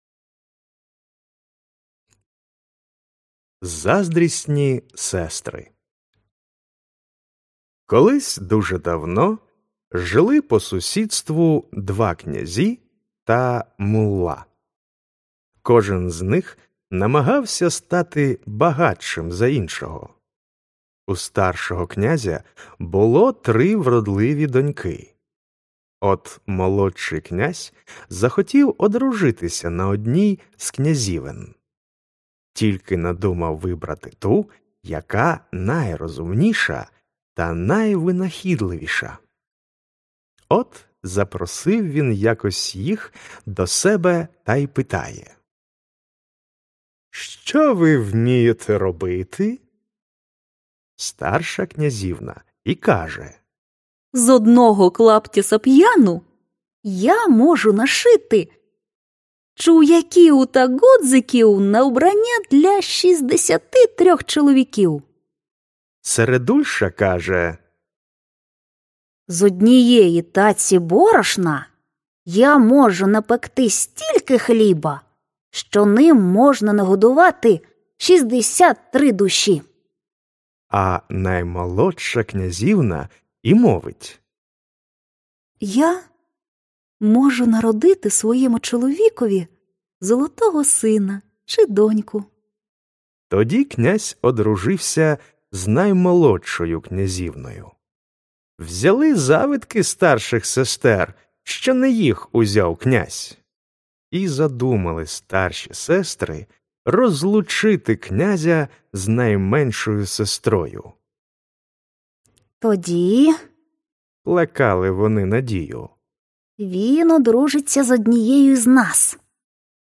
Аудіоказка Заздрісні сестри